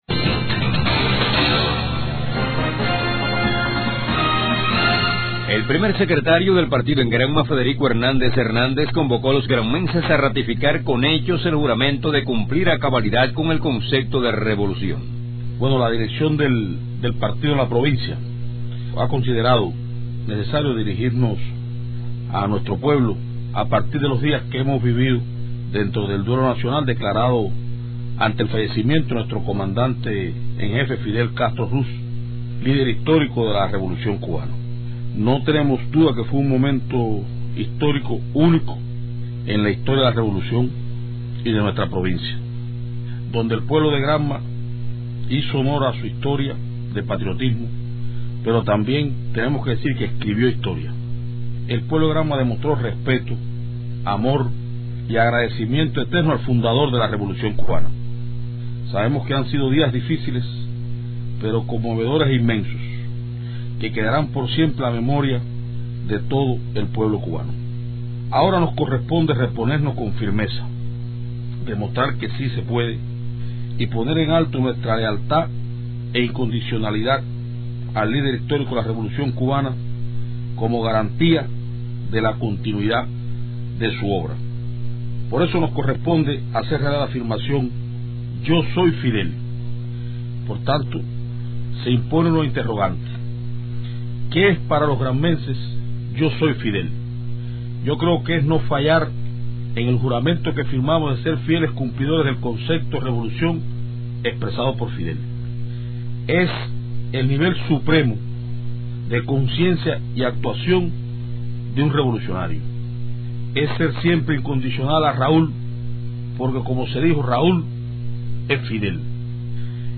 A esa demostración ineludible convocó este martes en Bayamo, capital de la suroriental provincia de Granma, Federico Hernández Hernández primer secretario del Partido en el territorio.
En alocución radial, el miembro también del Comité Central de ese organismo político, trasmitió al pueblo, a todos los sectores, un reconocimiento por las jornadas de tributo desarrolladas como homenaje póstumo al invicto líder Fidel Castro, caracterizadas por disciplina y organización.
Palabras-de-Federico-Hern--ndez-secretario-del-PCC-en-Granma.mp3